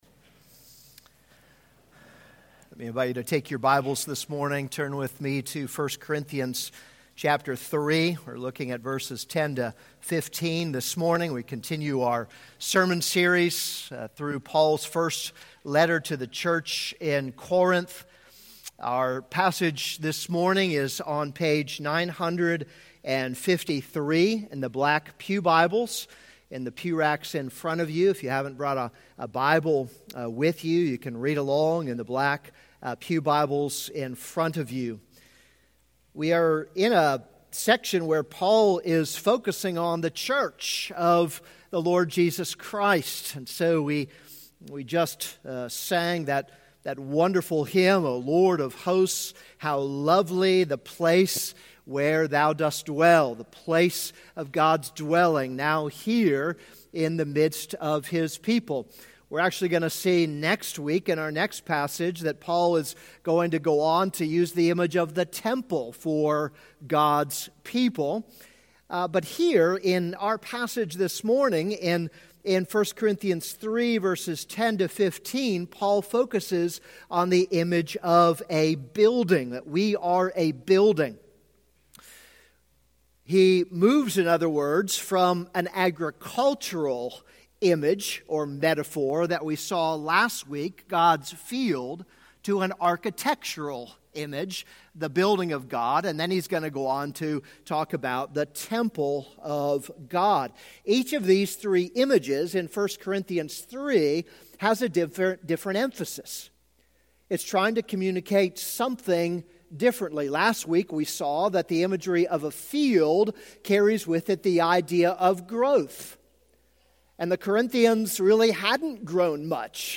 This is a sermon on 1 Corinthians 3:10-15.